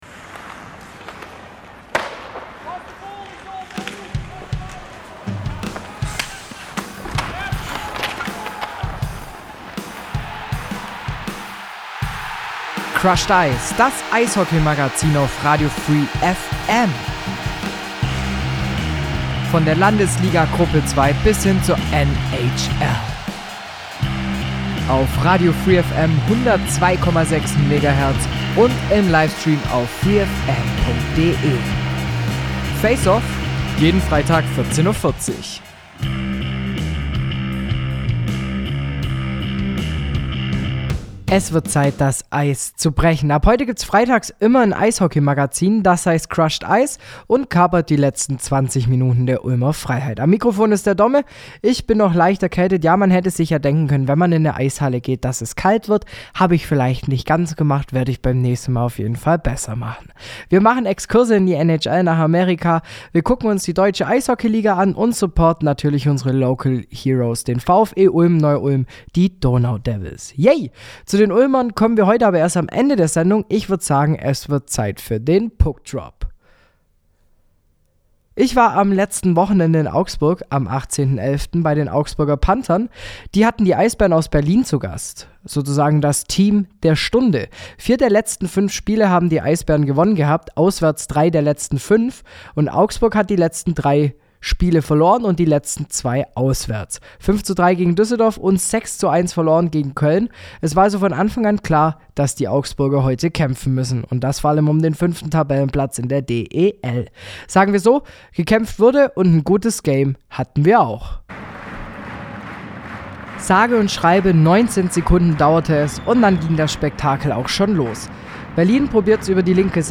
In der ersten Episode waren wir in Augsburg unterwegs. Die Augsburger Panther empfingen zum 19. Spieltag der DEL die Eisbären Berlin.